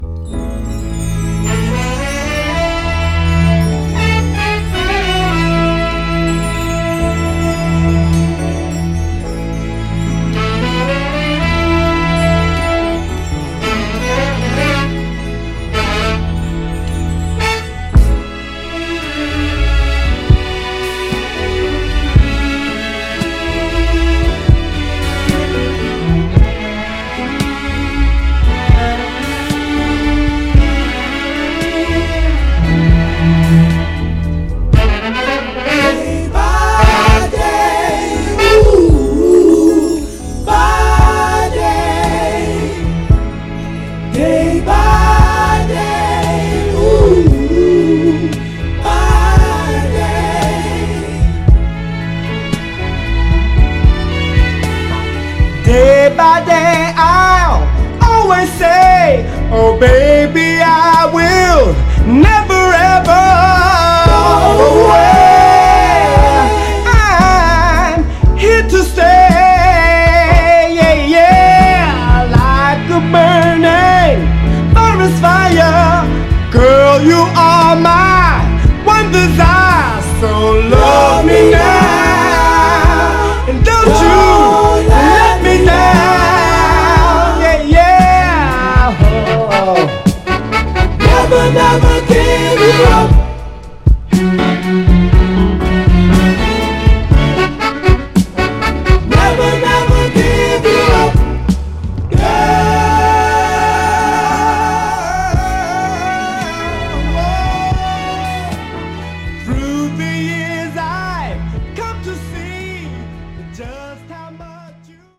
ストリングスとブラスのゴージャスなバックにとろける甘茶なヴォーカルが堪らない、極上メロウ/スウィート・ソウルです！
B面序盤でノイズ出ますが、肝心のA面はプレイ概ね良好です。
※試聴音源は実際にお送りする商品から録音したものです※